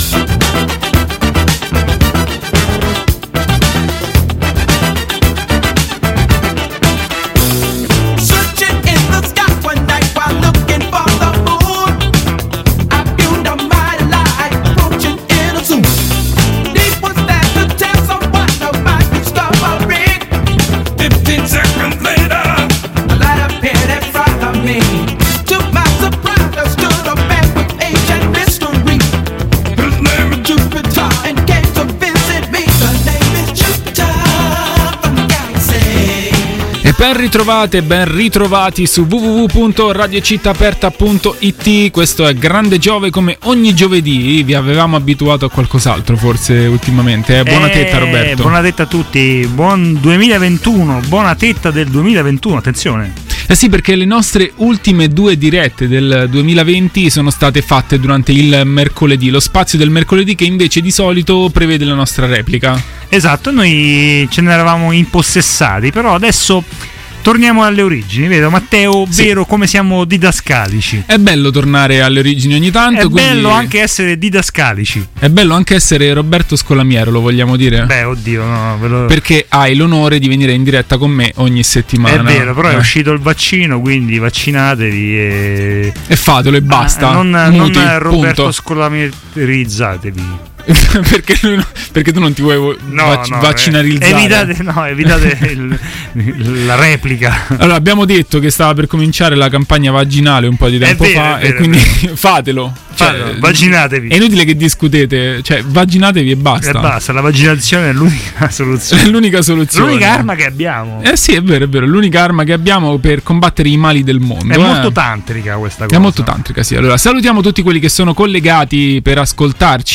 A tenere banco è stata la questione dei disordini a Capitol Hill, avvenuti dopo il discorso di Trump sul risultato delle elezioni americane. Comunque non è mancata la buona musica.